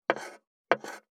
546魚切る,肉切りナイフ,まな板の上,
効果音厨房/台所/レストラン/kitchen食器食材